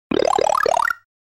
Snes Powerup Bouton sonore